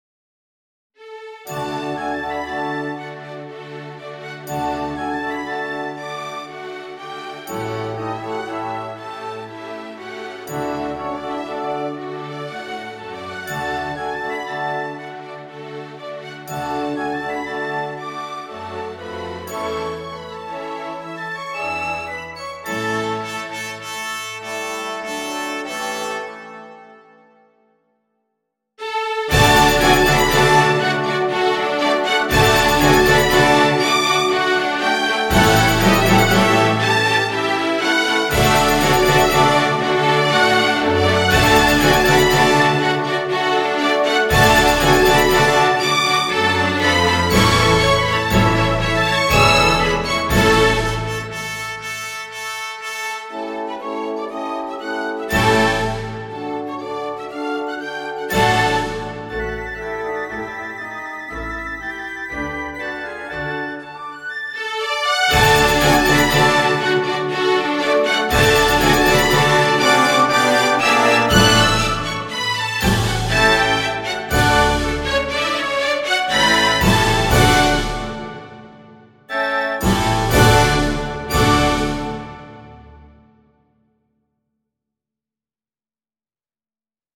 The Polacca
A polonaise is normally in 3/4 marked by characteristic rhythmic patterns (figs. 4-6).
In light of the foregoing, a computer-generated “performance” of the music (with NotePerformer software) is presented here, incorporating Saint-Léon’s inequality, articulations, ornaments and fermata.
The first seven bars (marked piano in the full score) serve as an introduction, wherein there is no dancing (fig. 10).